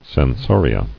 [sen·so·ri·a]